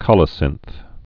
(kŏlə-sĭnth)